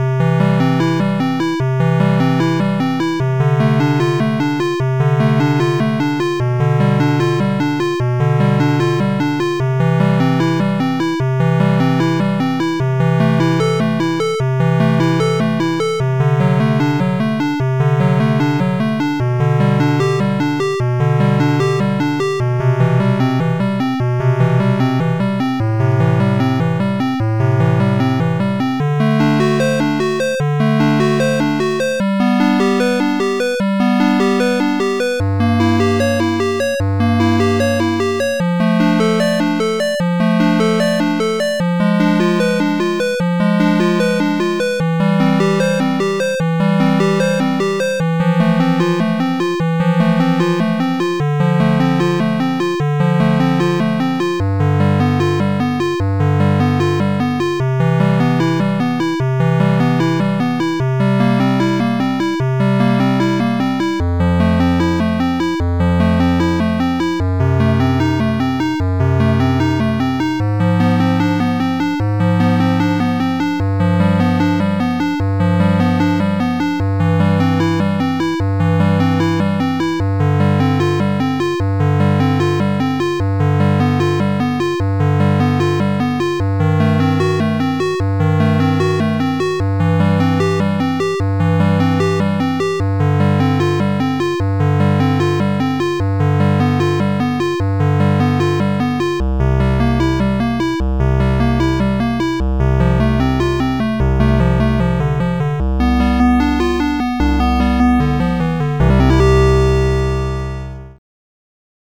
gameboy cover
it's bach's prelude in c major, but with gameboy bleeps and bloops. the piece features a meandering chord progression that goes through several moods for two minutes before resolving. the rhythm is simple and repetitive but doesn't get old because of the chords.